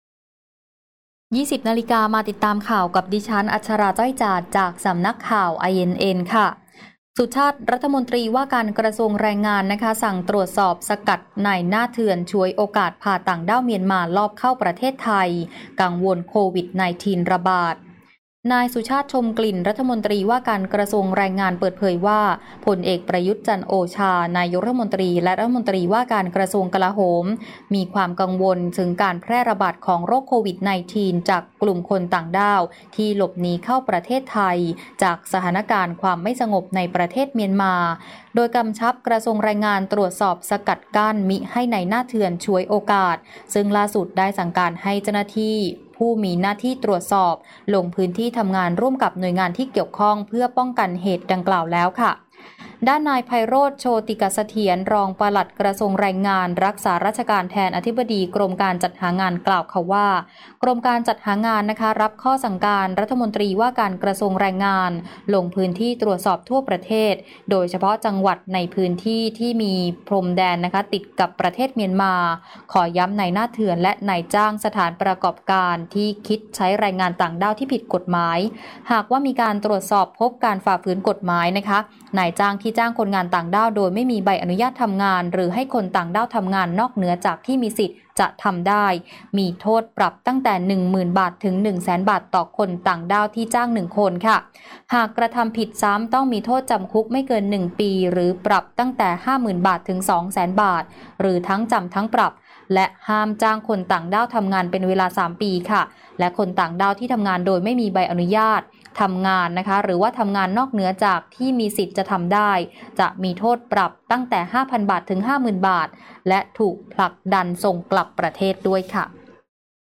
คลิปข่าวต้นชั่วโมง
ข่าวต้นชั่วโมง 20.00 น.